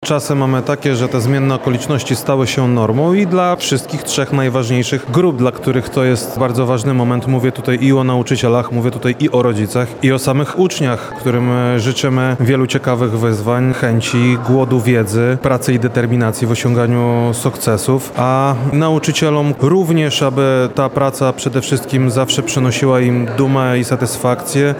W Krasnymstawie odbyła się wojewódzka inauguracja roku szkolnego 2025/2026. W całym regionie do szkół wróciło blisko 300 tysięcy uczniów.